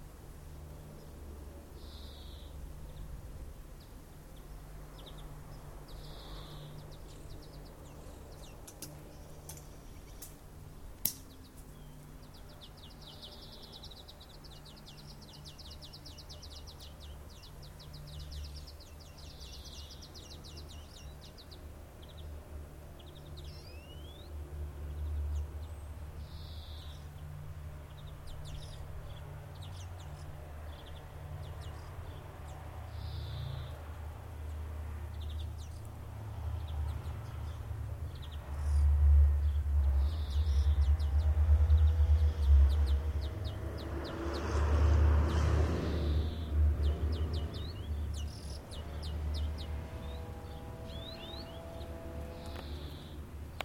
Taking a walk in June recording sounds around the village.